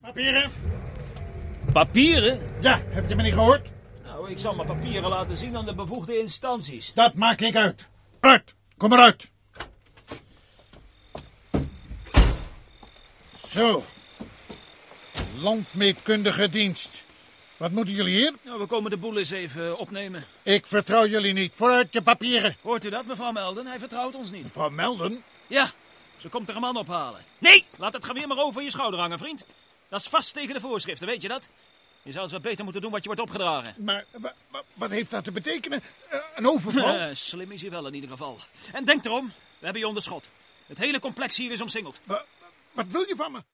Rol(len) in de hoorspelreeks: – De blauwe zaden – wacht